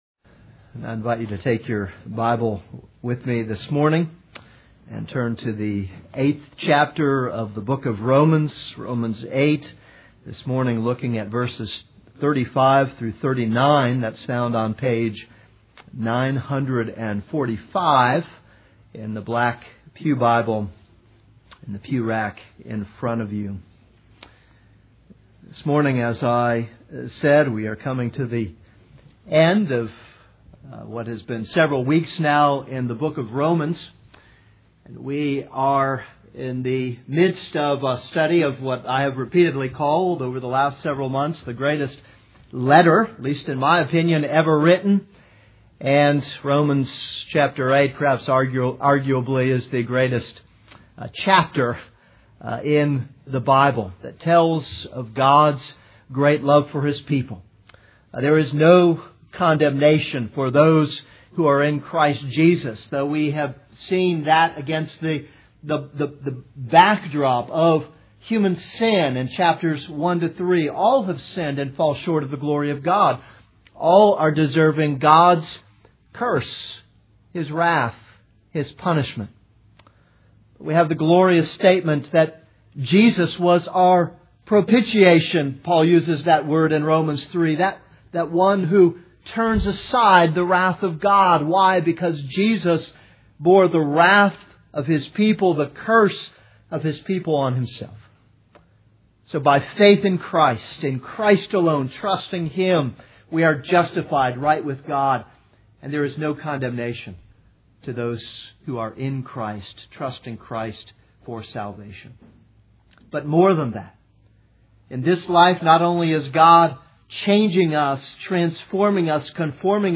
This is a sermon on Romans 8:35-39.